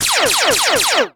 enemylasers2.ogg